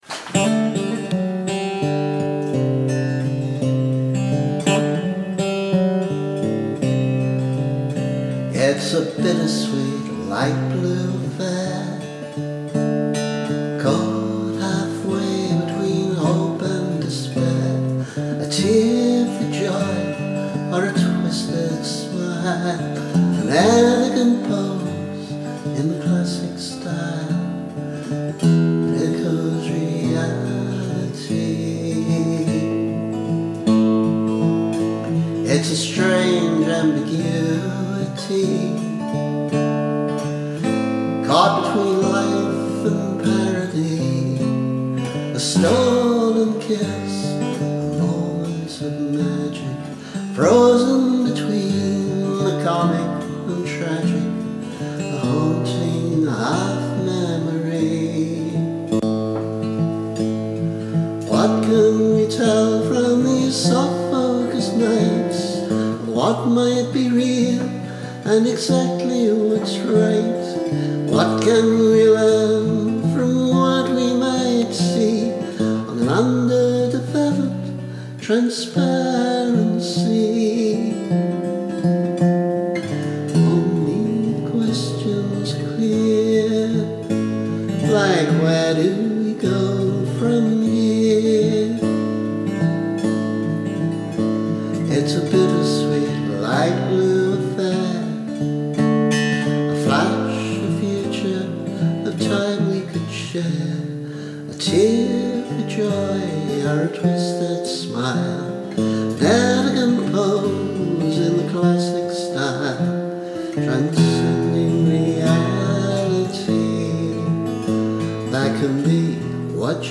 Freeze Frame [initial demo]